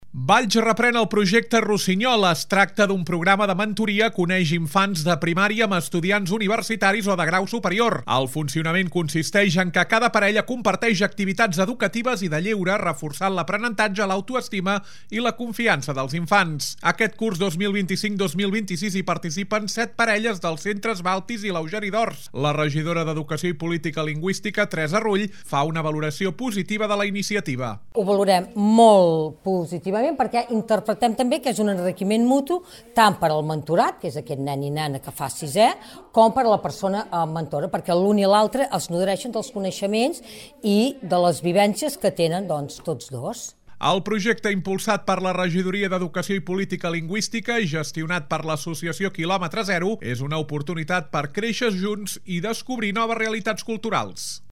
La regidora d’Educació i Política Lingüística, Teresa Rull, fa una valoració positiva de la iniciativa.